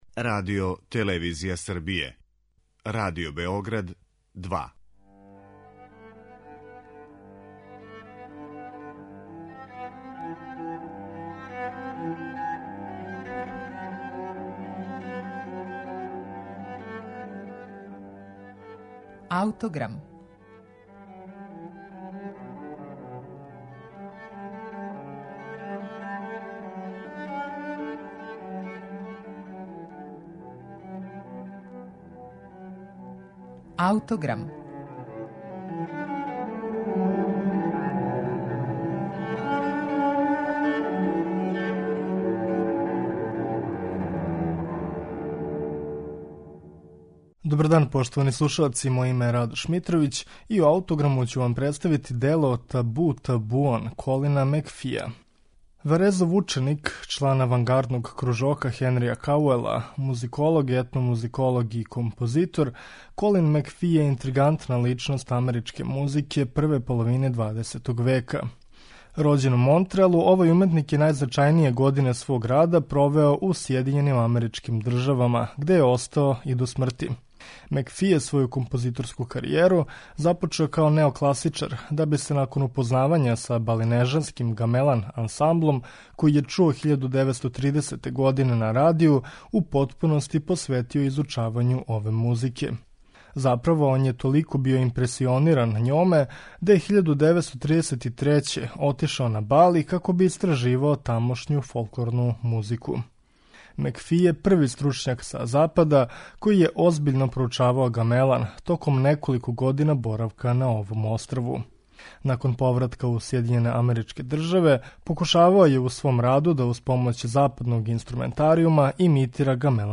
за оркестар и два клавира